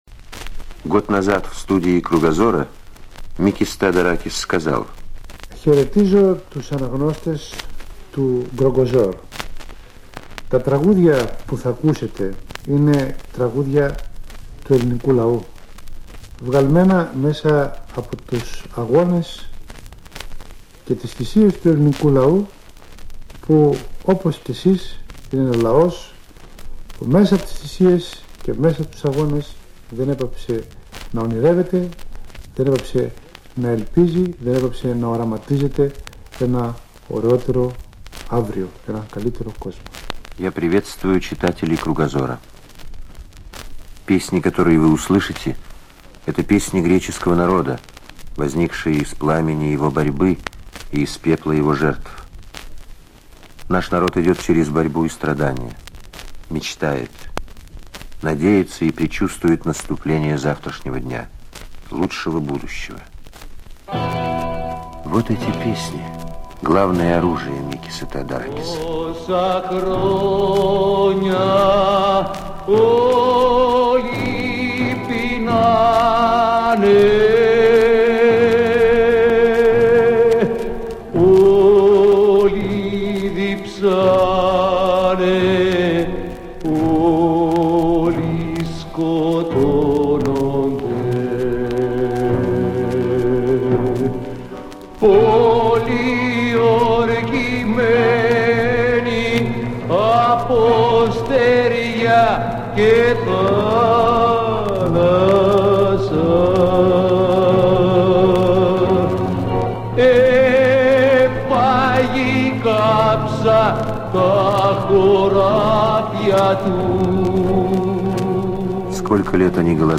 В студии радиодома на Пятницкой говорил Микис Теодоракис.
Человек огромного роста - он говорил тихо. Было странно слышать тембр его голоса - глухой и высокий.
Голос Микиса Теодоракиса слушайте на третьей звуковой странице.